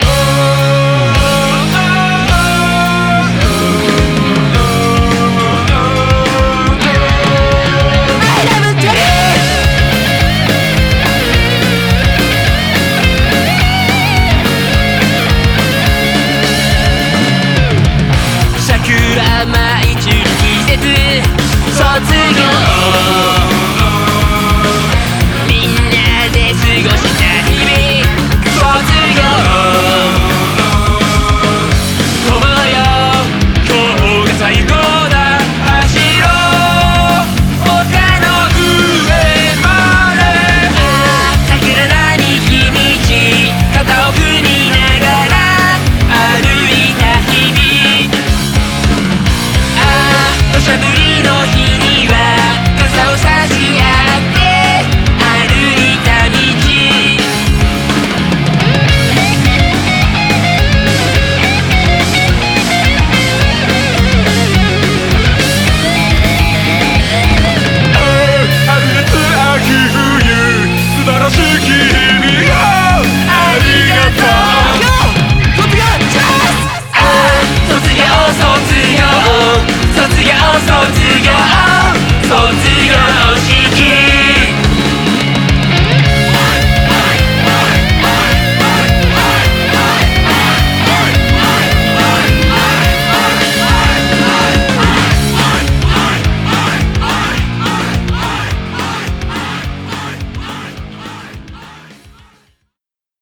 BPM106-212
Audio QualityPerfect (High Quality)